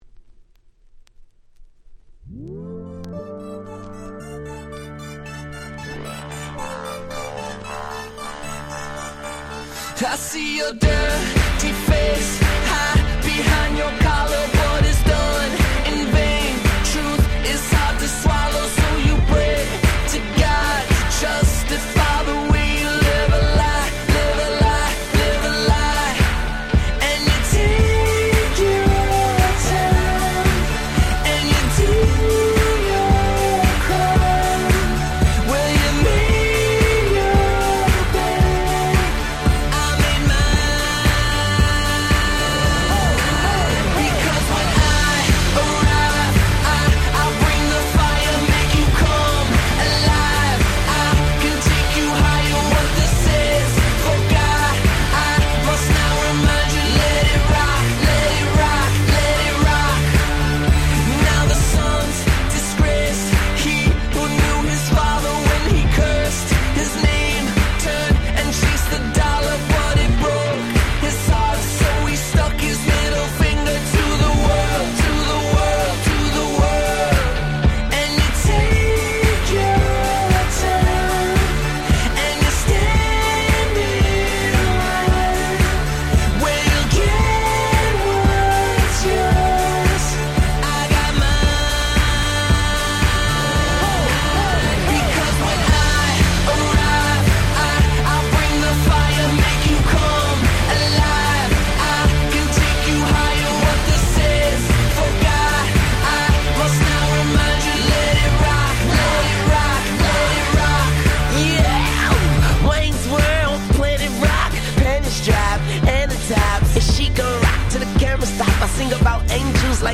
08' Super Hit R&B !!